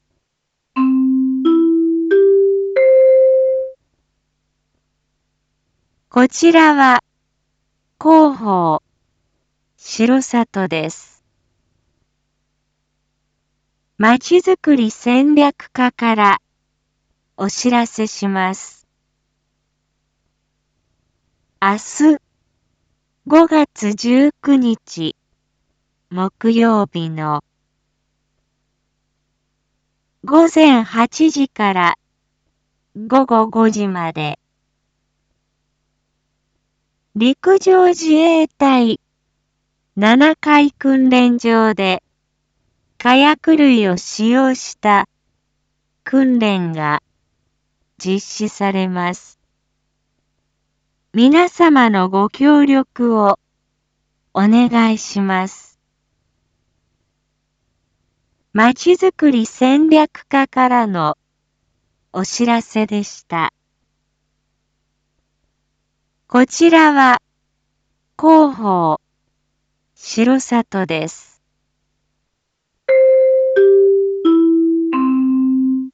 Back Home 一般放送情報 音声放送 再生 一般放送情報 登録日時：2022-05-18 19:06:17 タイトル：R4.5.18 19時放送分 インフォメーション：こちらは広報しろさとです。